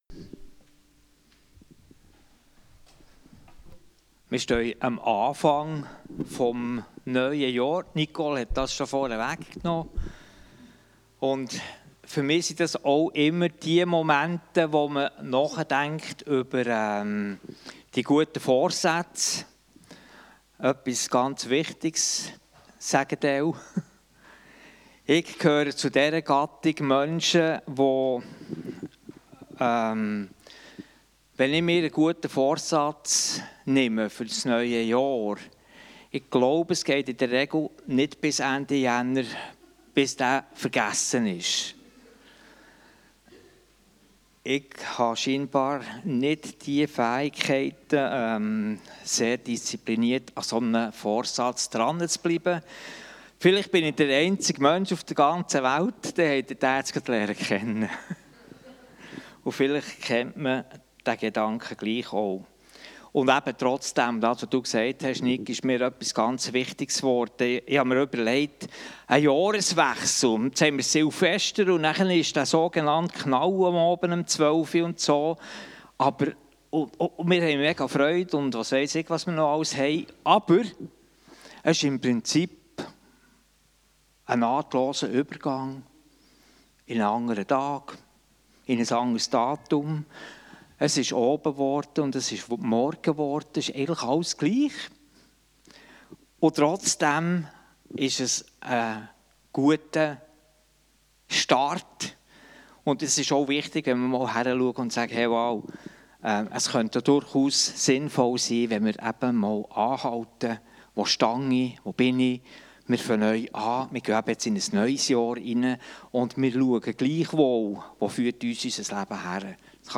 Passage: Matthäus 17. 1-8 Dienstart: Gottesdienst Themen